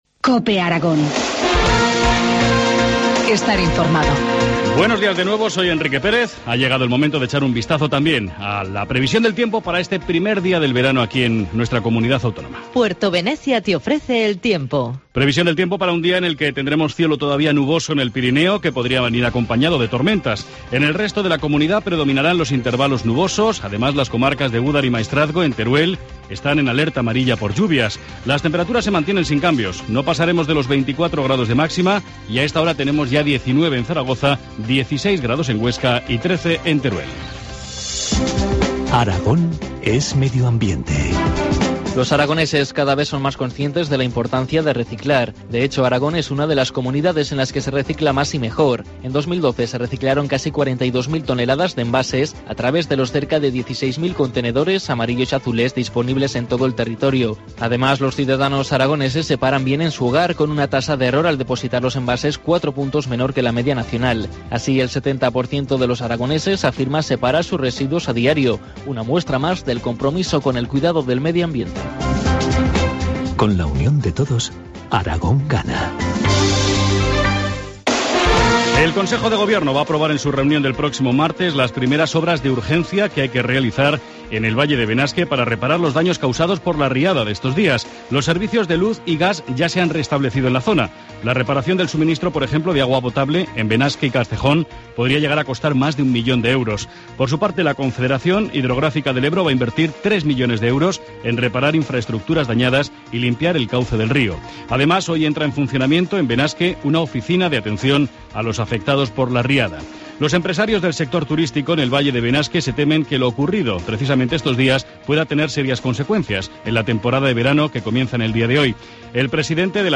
Informativo matinal, viernes 14 de junio, 8.25 horas